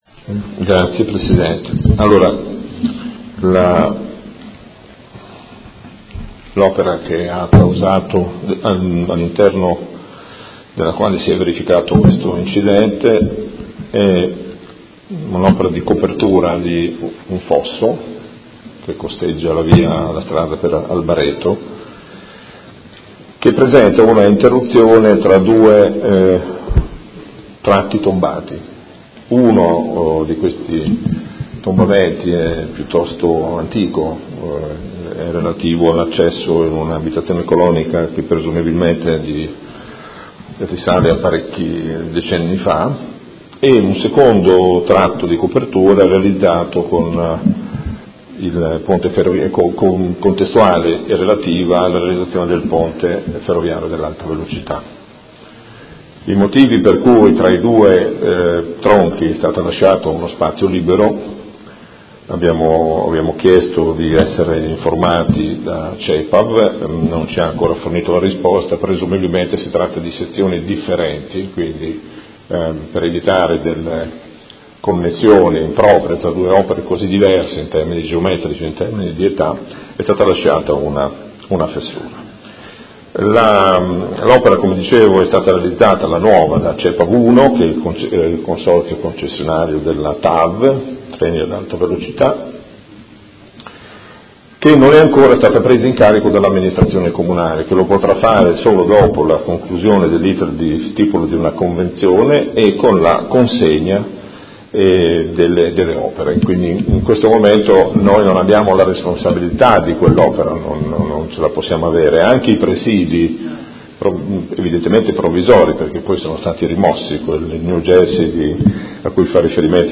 Gabriele Giacobazzi — Sito Audio Consiglio Comunale
Seduta del 13/07/2017 Risponde. Interrogazione del Consigliere Stella (Art.1-MDP) avente per oggetto: Grave incidente occorso a ciclista dodicenne lungo la Strada Albareto